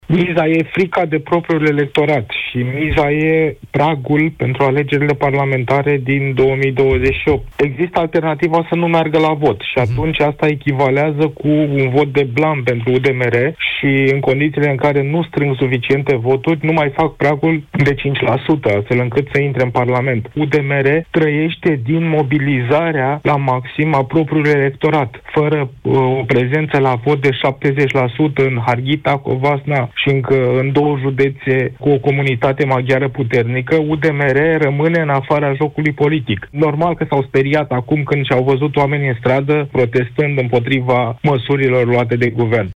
într-o intervenție la matinalul EUROPA FM.